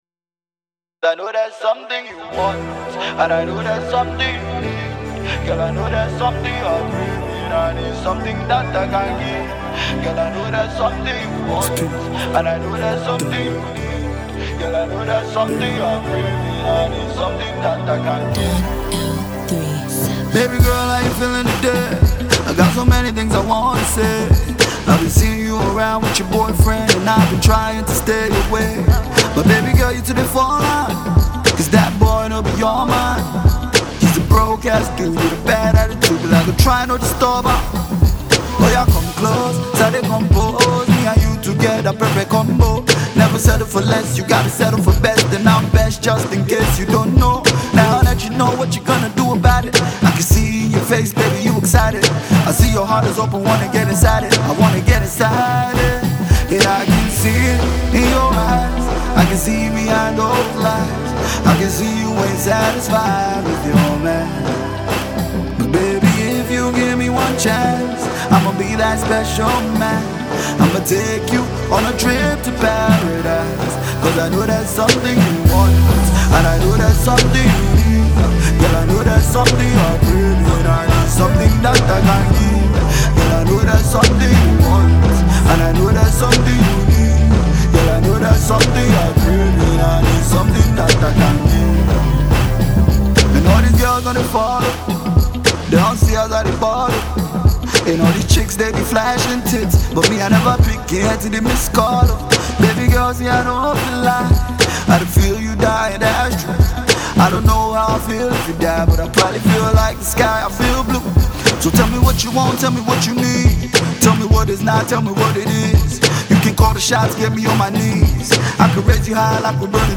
is a groovy track